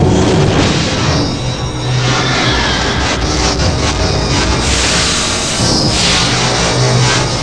rings.wav